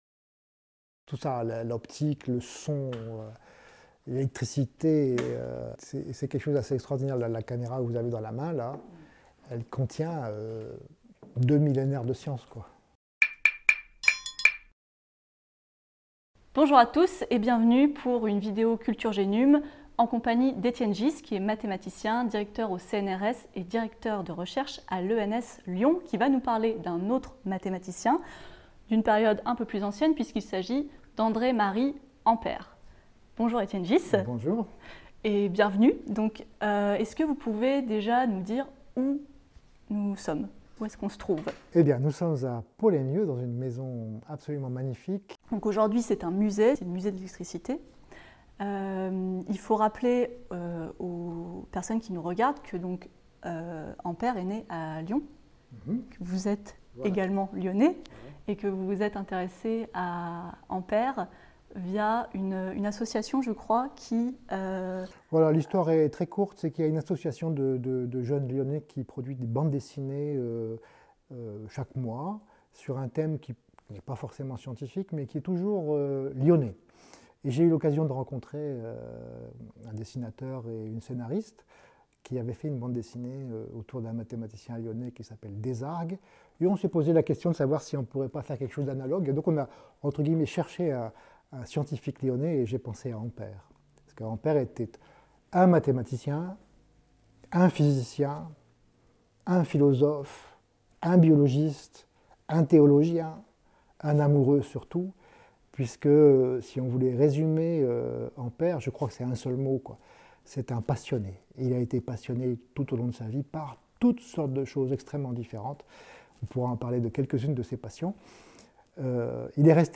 au musée Ampère de Poleymieux (Rhône).